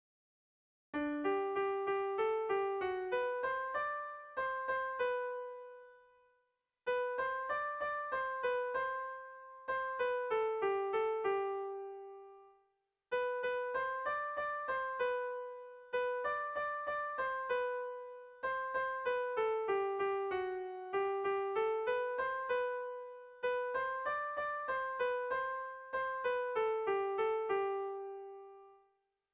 Erlijiozkoa
Hamarreko txikia (hg) / Bost puntuko txikia (ip)
ABDEB